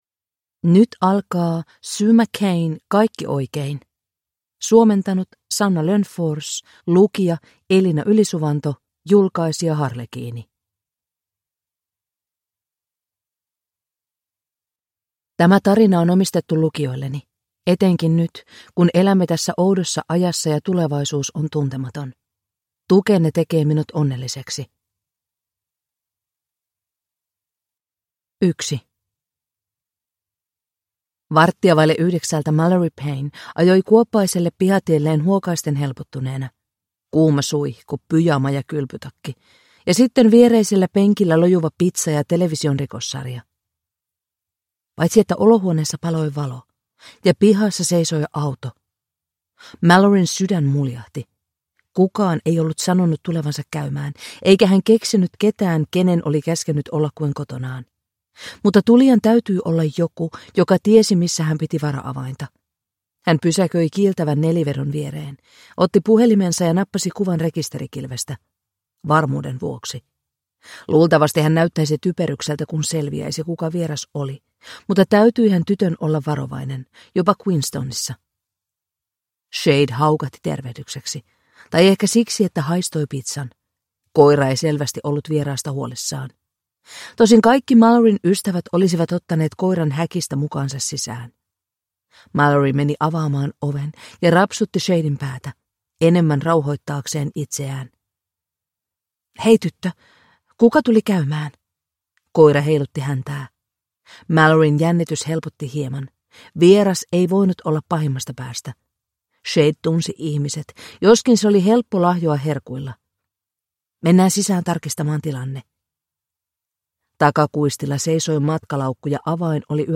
Kaikki oikein (ljudbok) av Sue MacKay